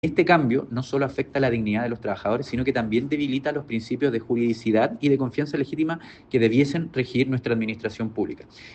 El parlamentario indicó que no es permisible que decisiones administrativas vayan en contra de la estabilidad laboral de quienes trabajan al servicio del Estado: